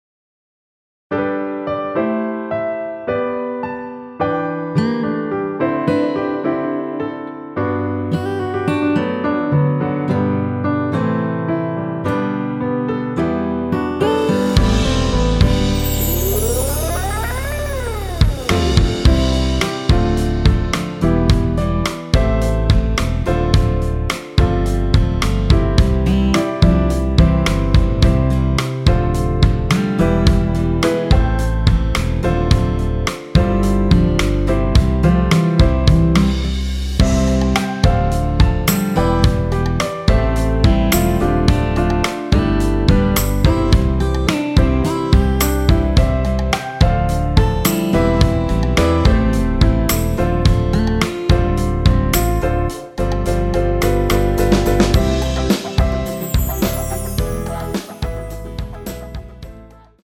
대부분의 남성분이 부르실 수 있는 키로 제작하였습니다.(미리듣기 확인)
원키에서(-7)내린 MR입니다.
앞부분30초, 뒷부분30초씩 편집해서 올려 드리고 있습니다.